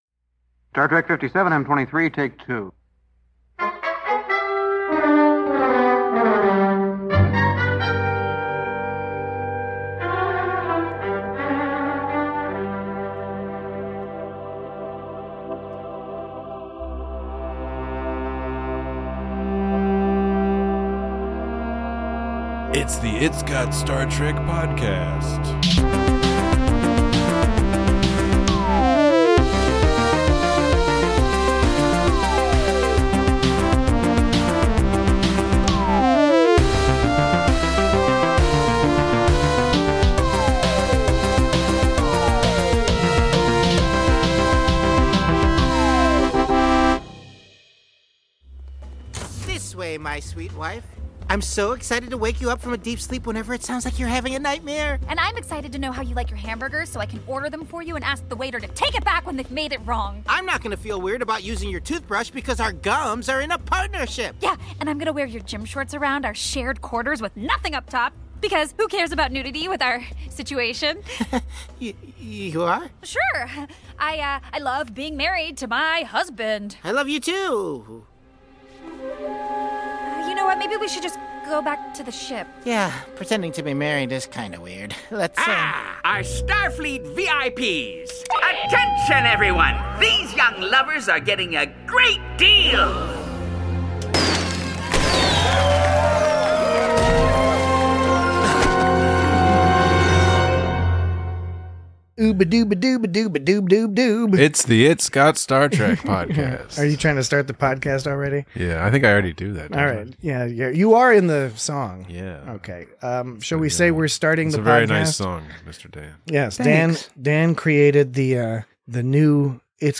Tendi and Rutherford navigate an awkward situation while other members of the crew encounter a variety of separate challenges. Join your latinum-craving hosts as they discuss Orion blushes, lieutenants junior grade, and the Hitchhiker's Guide to the Galaxy.